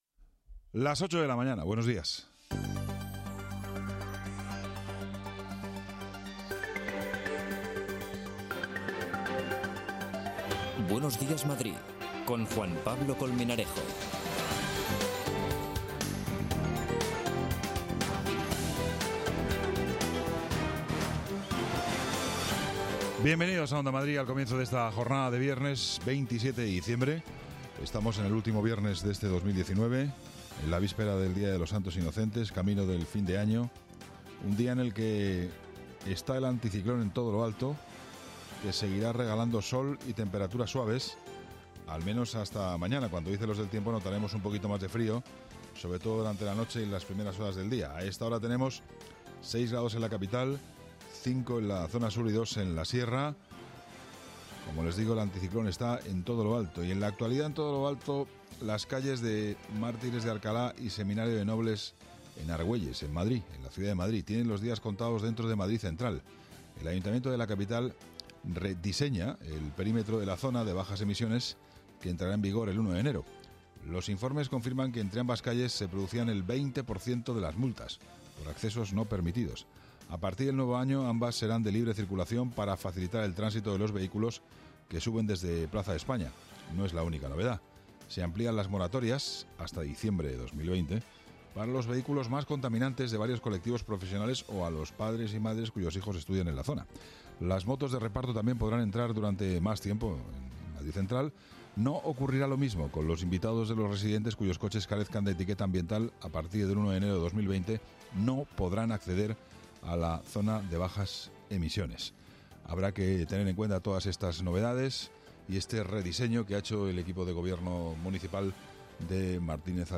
Actualidad, opinión, análisis, información de servicio público, conexiones en directo, entrevistas…